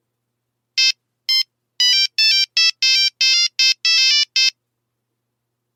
Мелодия на Nokia 1100